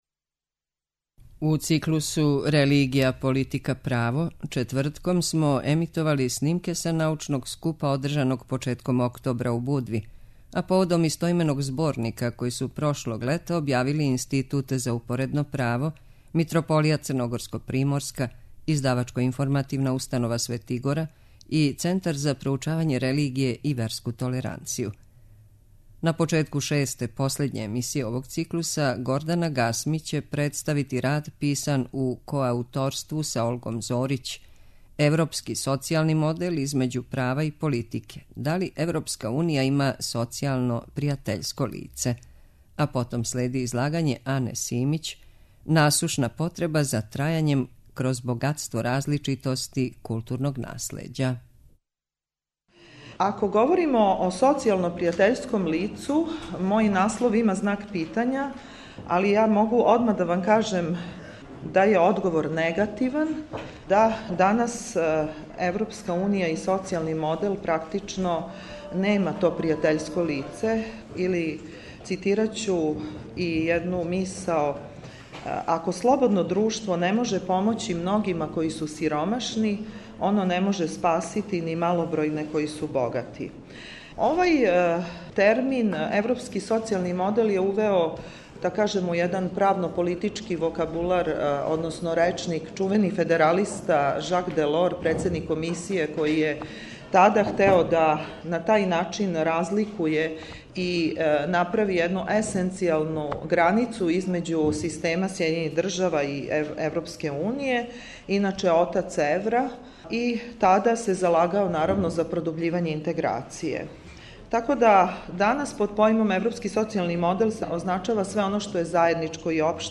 У циклусу РЕЛИГИЈА, ПОЛИТИКА, ПРАВО четвртком смо емитовали снимке са међународног научног скупа одржаног поводом представљања истоименог зборника научних радова.